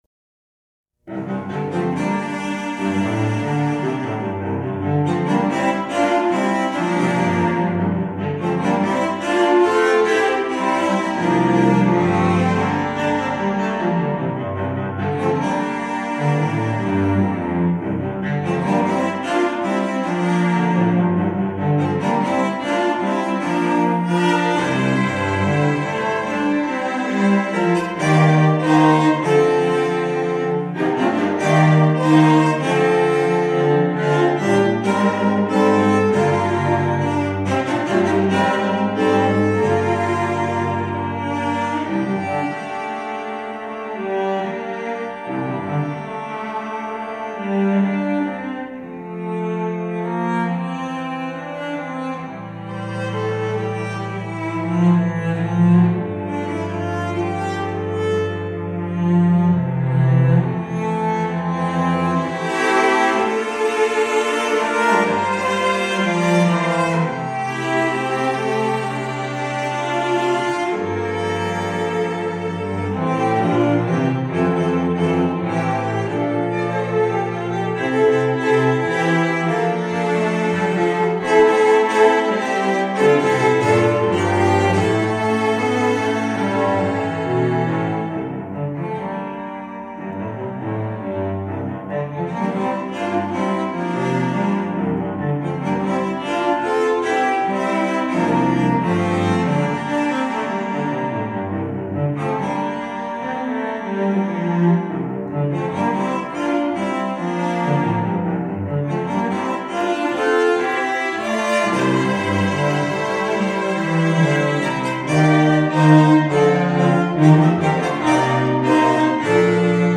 Voicing: Cello Quartet